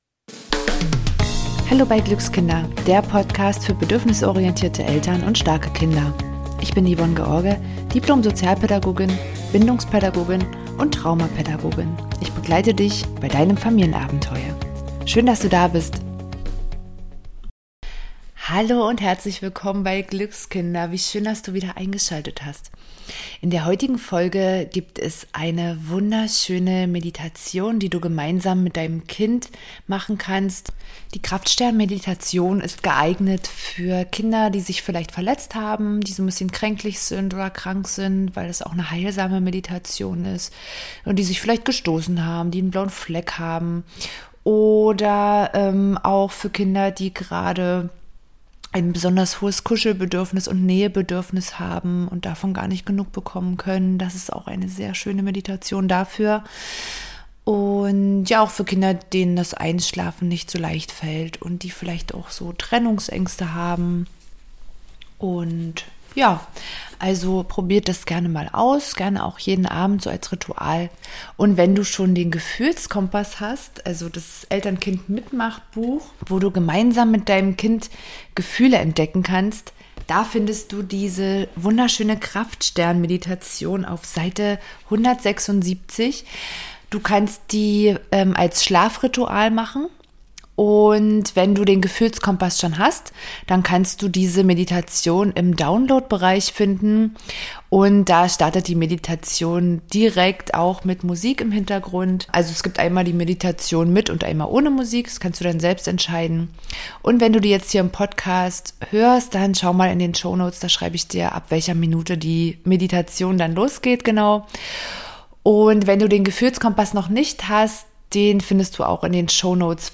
#54 Kraftstern Meditation für Kinder - besser einschlafen | heilsam bei Angst und Schmerzen